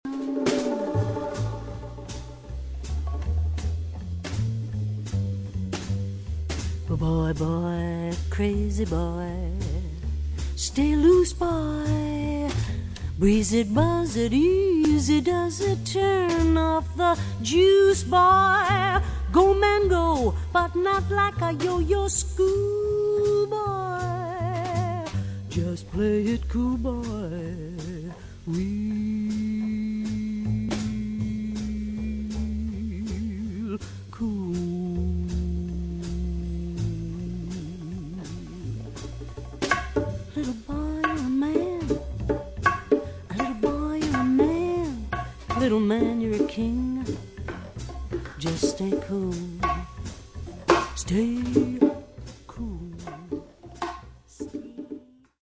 Vocals
Piano
Flugelhorn
Bass
Harmonica
National Guitar
Drums
Percussion
Cello